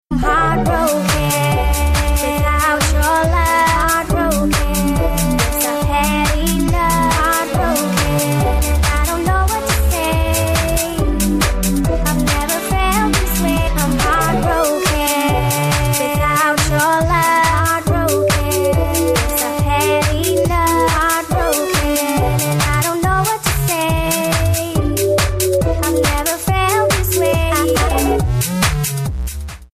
Tags: Sound Effects Ringtones Techno Dance True Tones